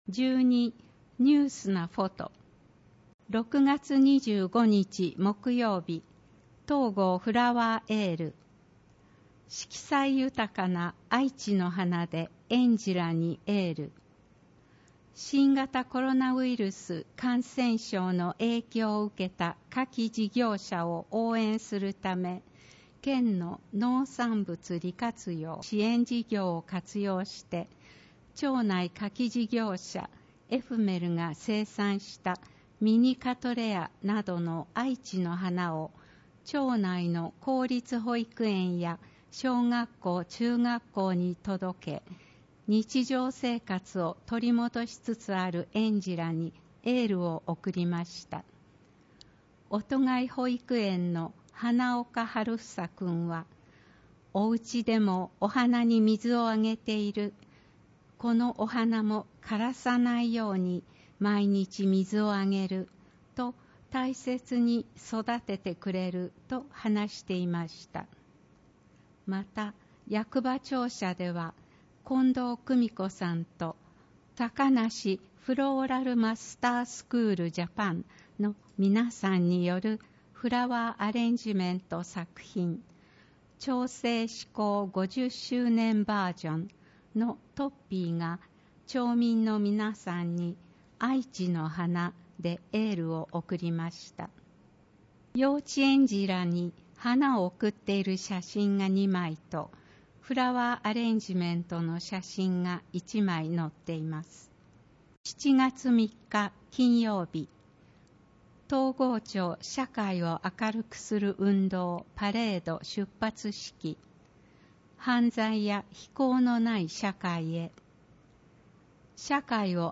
広報とうごう音訳版（2020年9月号）